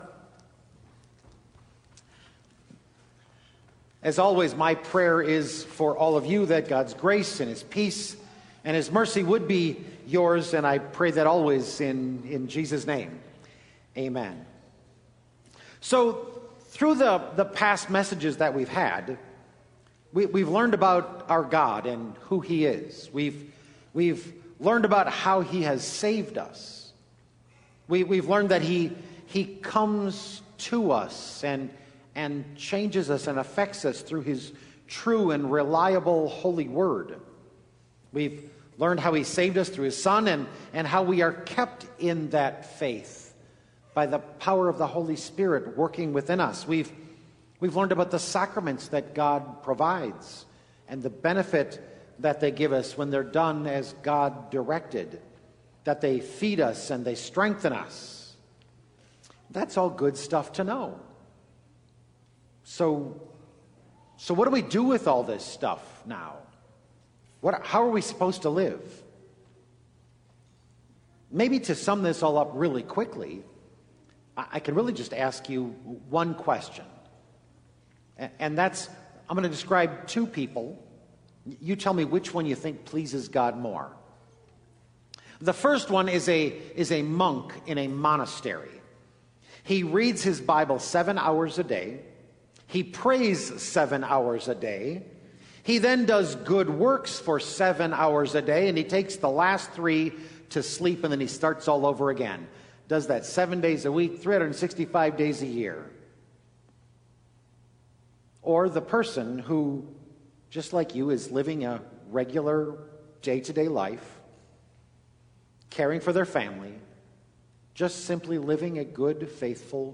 08-05-Good-News-Living-A-Devotional-Life-Sermon-Audio.mp3